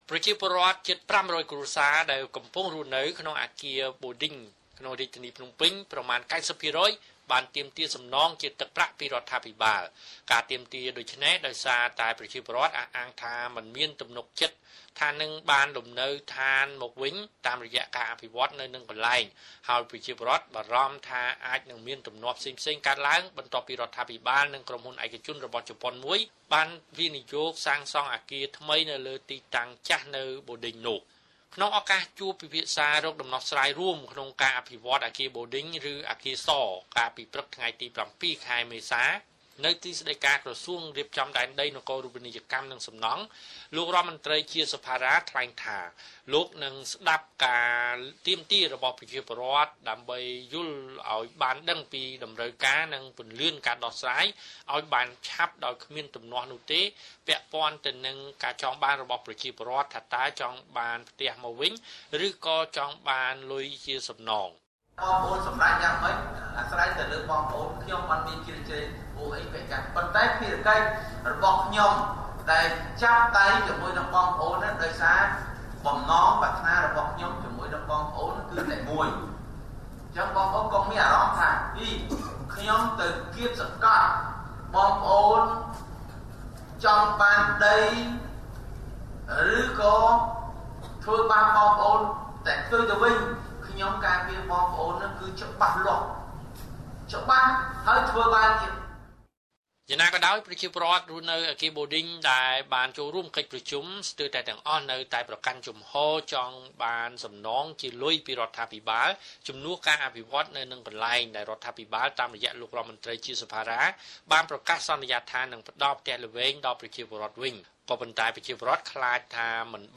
ជូនសេចក្តីរាយការណ៍បន្ថែម។